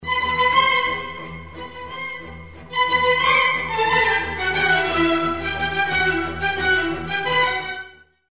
cartoon